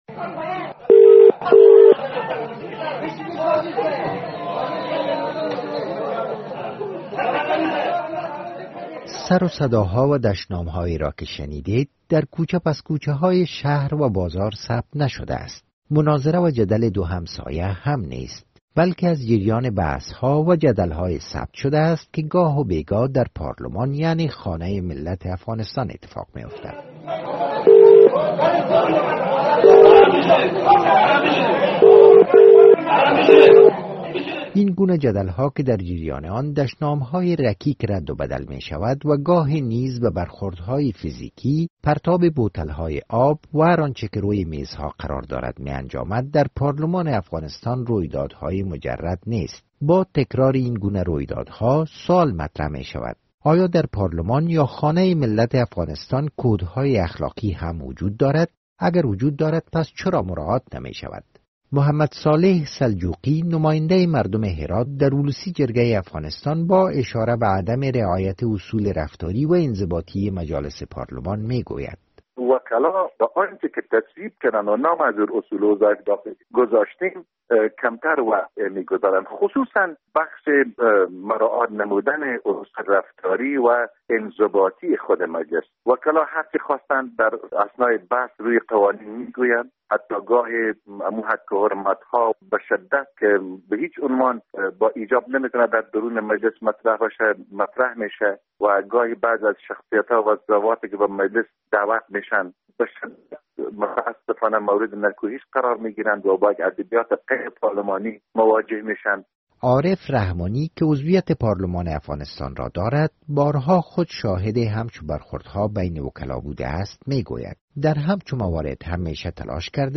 بعضی سر و صدا و دشنامها که شنیده میشوند در کوچه، پسکوچهها و بازار ثبت نشدهاند؛ مناظره و جدل دو همسایه هم نیستند، بلکه از جریان بحثها و جدلهای ثبت شدهاند که گاه و بیگاه در پارلمان یعنی خانه ملت افغانستان، اتفاق میافتند.